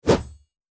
attack_both_wp_2.mp3